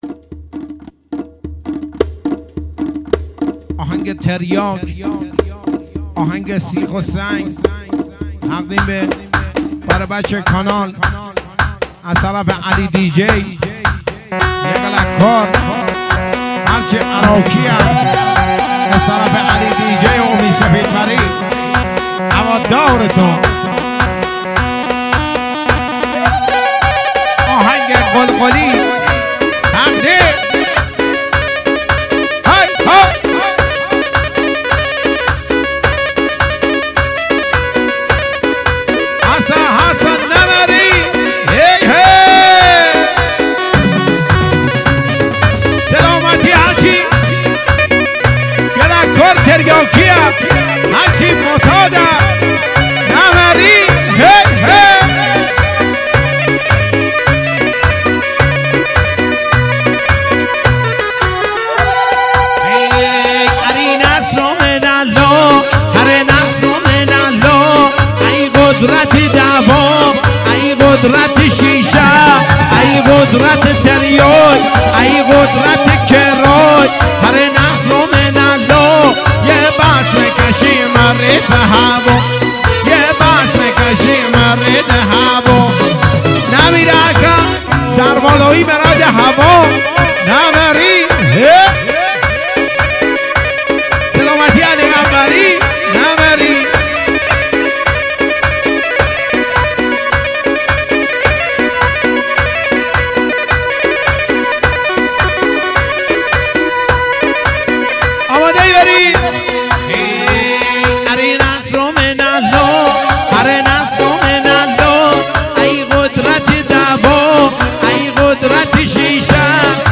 ورژن اصلی